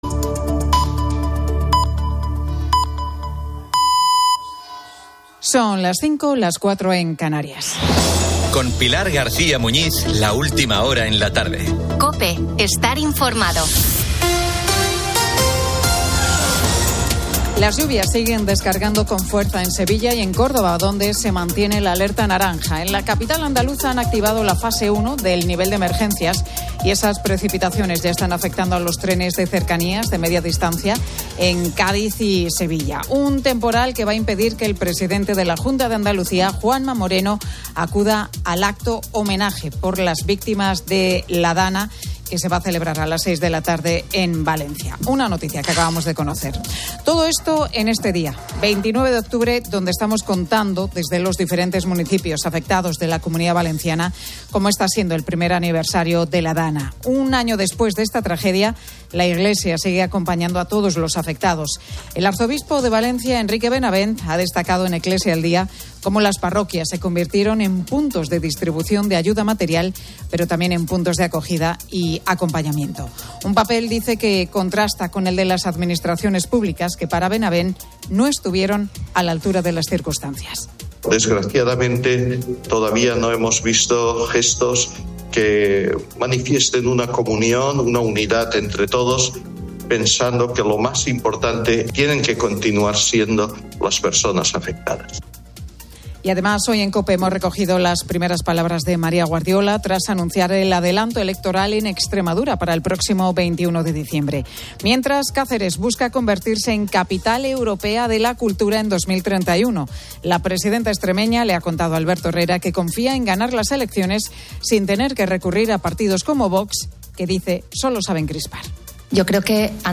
La Tarde 17:00H | 29 OCT 2025 | La Tarde Pilar García Muñiz presenta La Tarde desde el Centro de Escucha de la Parroquia Nuestra Señora de la Asunción, en Torrent, con motivo del primer aniversario de la DANA.